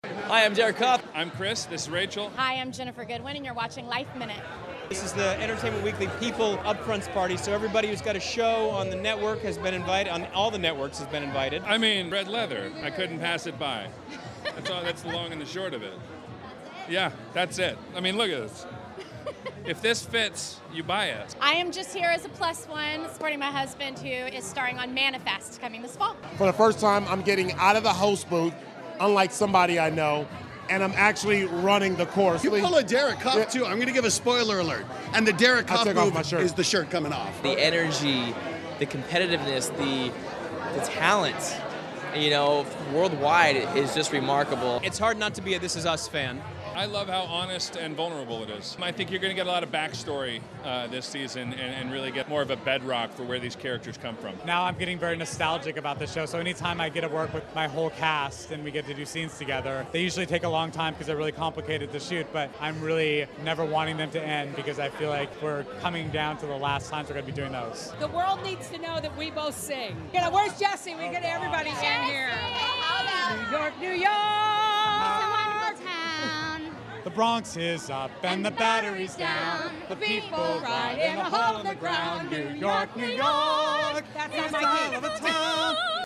If you aren't familiar the "Upfronts" it's is an annual event where television executives show advertisers their planned programming for the upcoming season. This year we spotted Mandy Moore, Jaimie Alexander, Scott Foley, Chris Sullivan, Ginnifer Goodwin, Akbar Gbaja-Biamila, Matt Iseman, Derek Hough, Jesse Tyler Ferguson, Lea DeLaria, Kristin Chenoweth and many more at the Entertainment Weekly & PEOPLE Magazine VIP party at The Bowery Hotel in New York City.